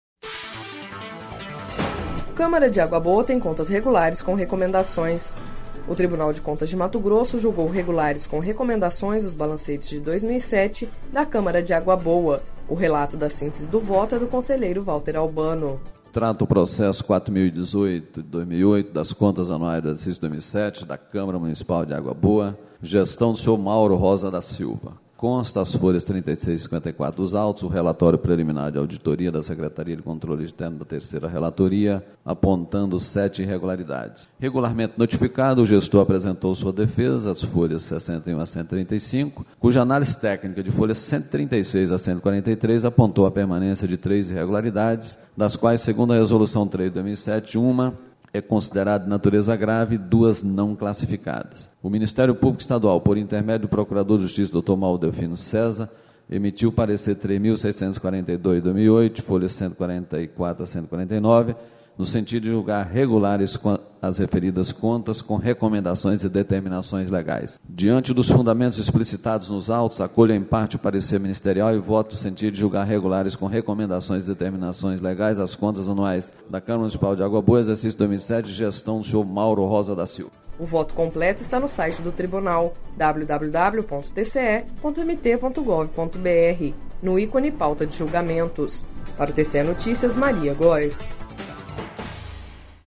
O Tribunal de Contas de Mato Grosso julgou regulares com recomendações os balancetes de 2007 da Câmara de Água Boa./ O relato da síntese do voto é do conselheiro Valter Albano.